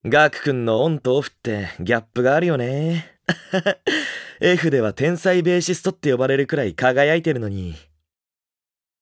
蘇芳 楽（CV:梶 裕貴）のキャラクター紹介 | イケメンライブ 恋の歌をキミに（イケラブ）Love song for you
voice_introduction_01.mp3